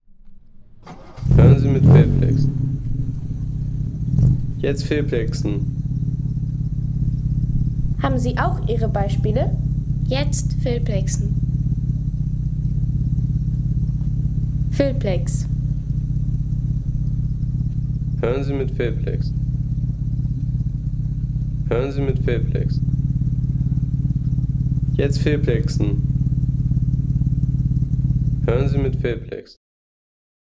Jaguar XK 140 DHC - 1955